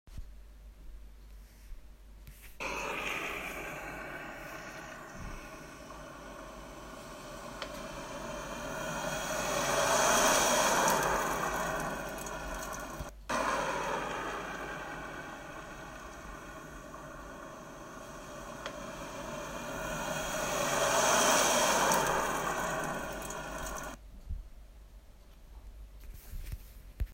Audio: Every now and again, cars pass the bus stop while we wait for the bus to eventually arrive.